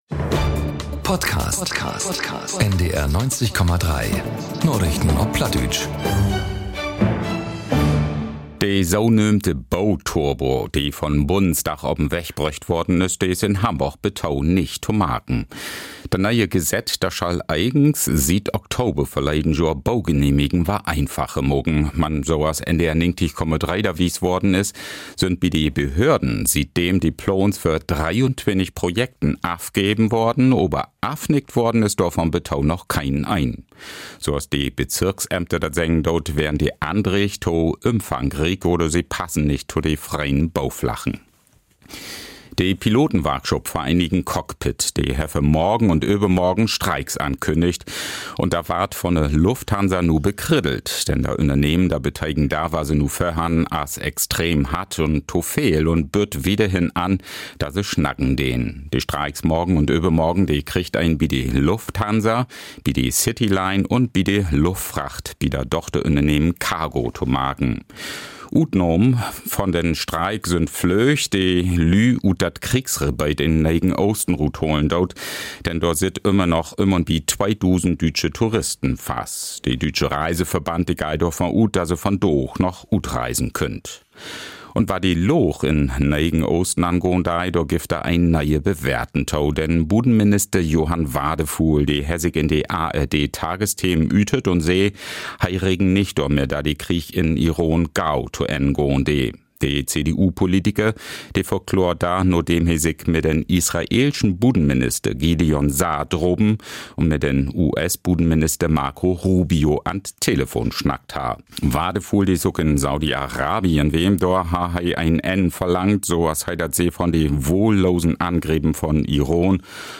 Narichten op Platt 11.03.2026 ~ Narichten op Platt - Plattdeutsche Nachrichten Podcast